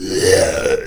spawners_mobs_uruk_hai_neutral.2.ogg